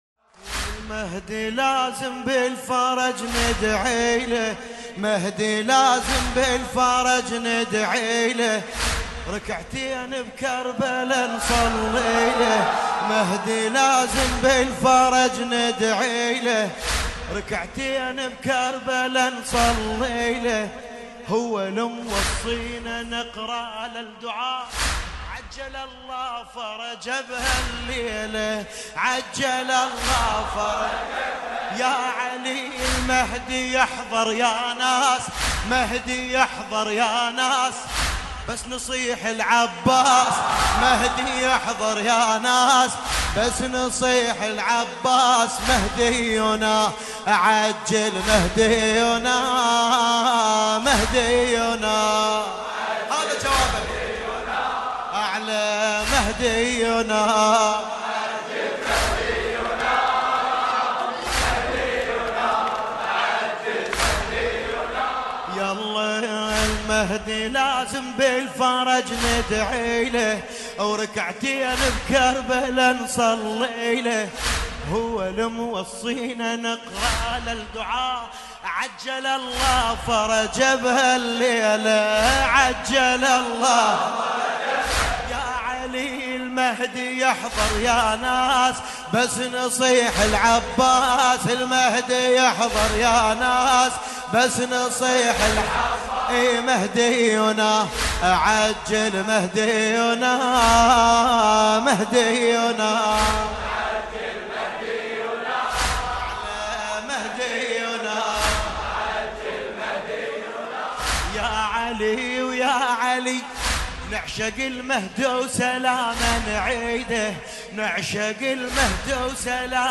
المهدي - شور